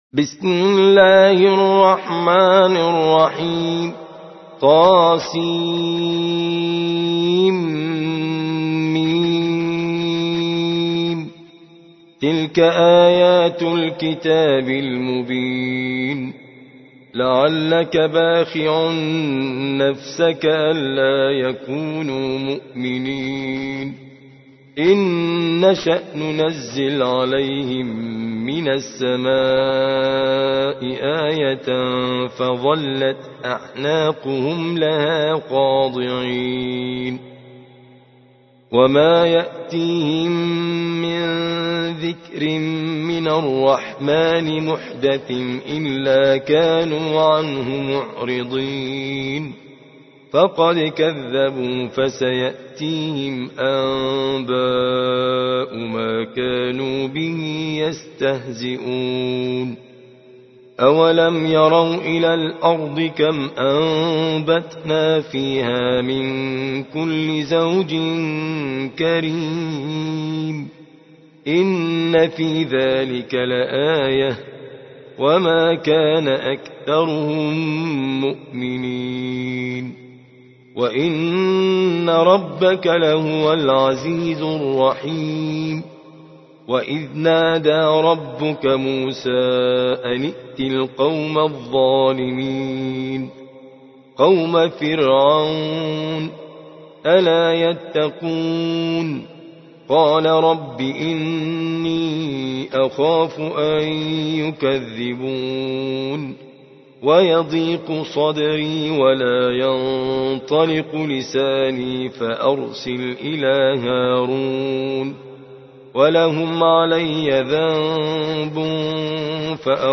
26. سورة الشعراء / القارئ